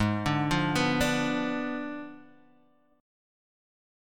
G#dim7 chord